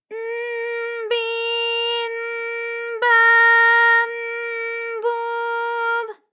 ALYS-DB-001-JPN - First Japanese UTAU vocal library of ALYS.
b_n_bi_n_ba_n_bu_b.wav